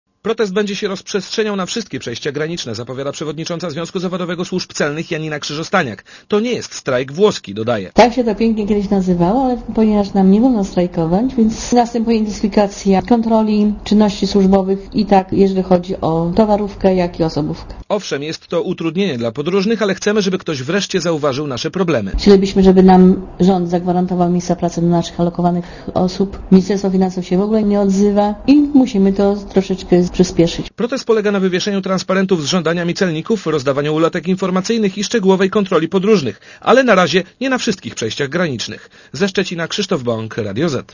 Komentarz audio (160Kb)